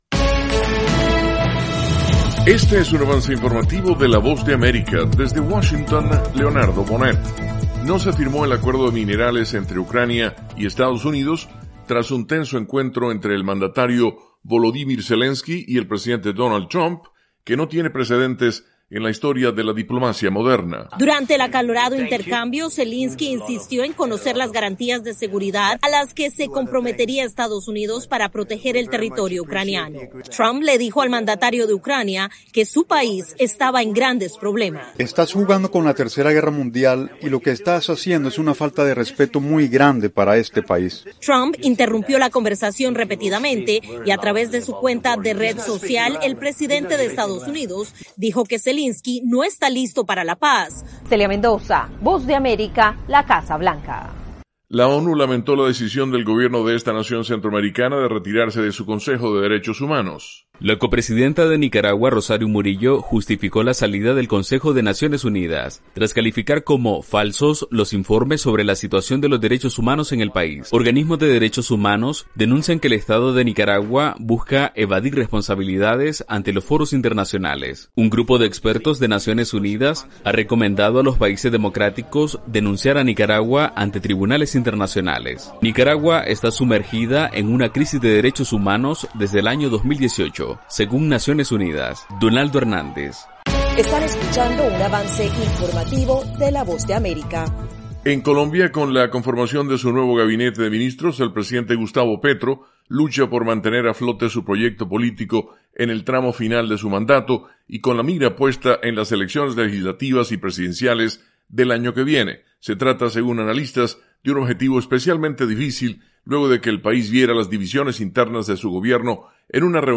Avance Informativo
El siguiente es un avance informativo presentado por la Voz de América